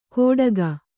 ♪ kōḍaga